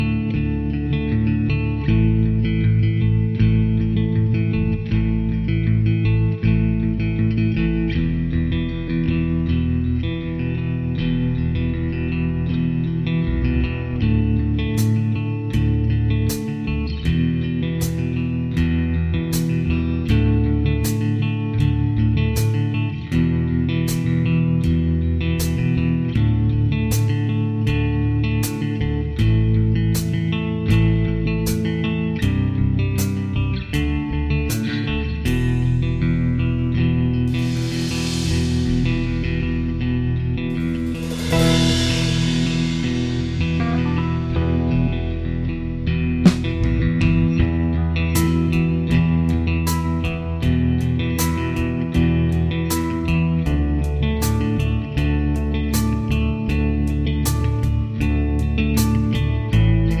Minus Saxophone Soft Rock 4:58 Buy £1.50